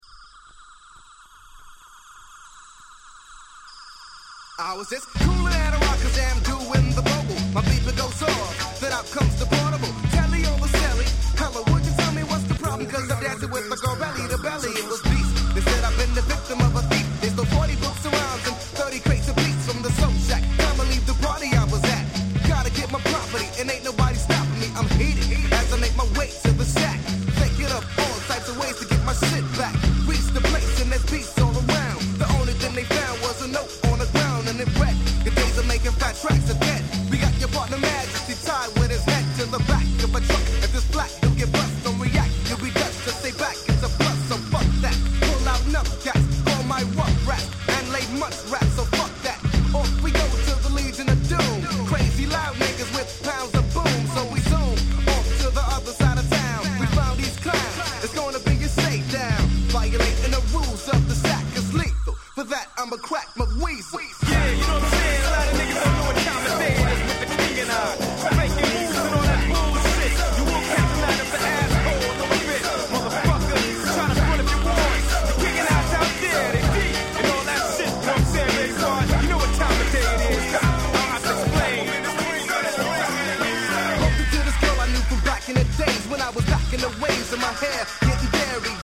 93' Nice Hip Hop !!